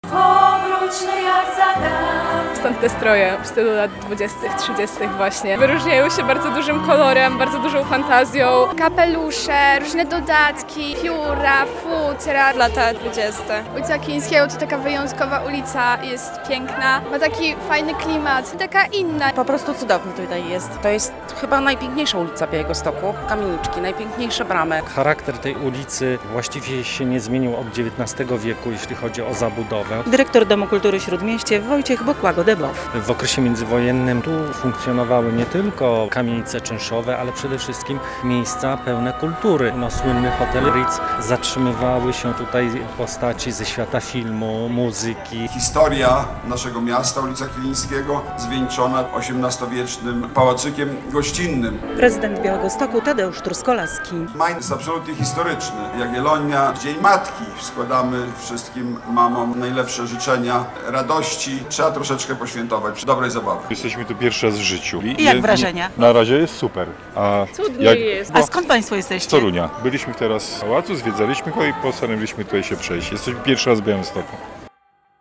W Białymstoku trwa święto ulicy Kilińskiego - relacja